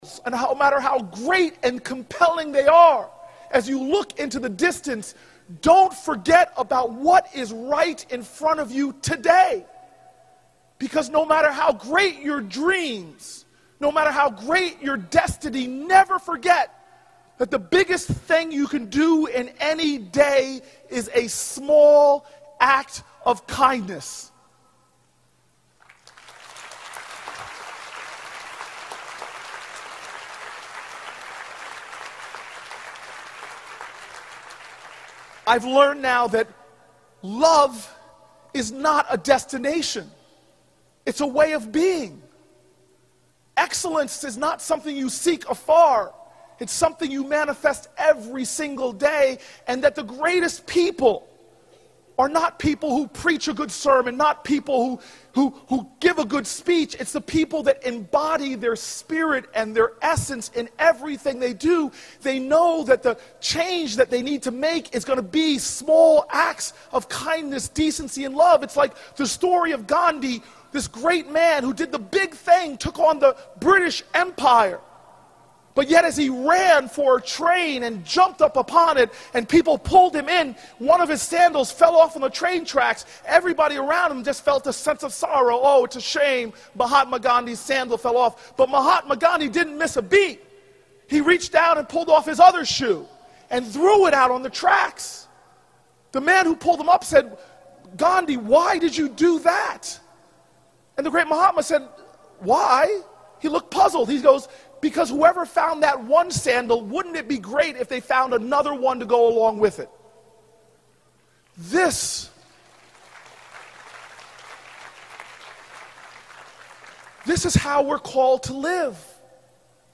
公众人物毕业演讲第451期:科里布克2013年耶鲁大学(19) 听力文件下载—在线英语听力室